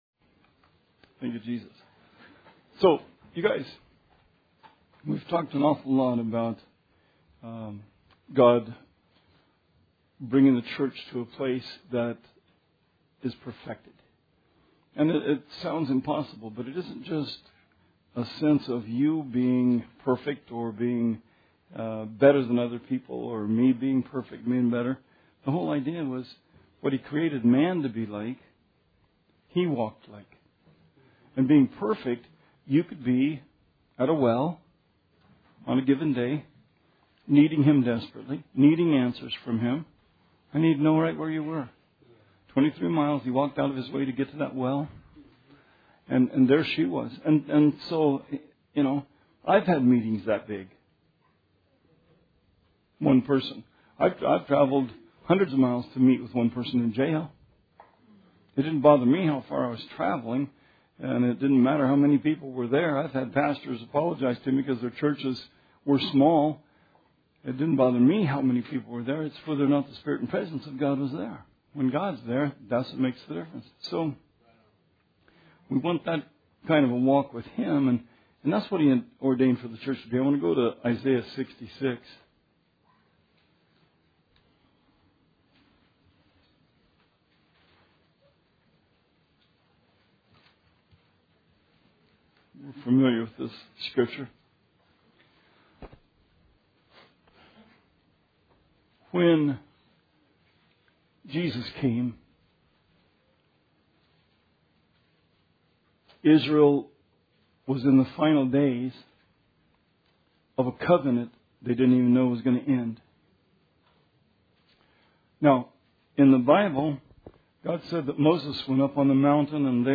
Bible Study 6/7/17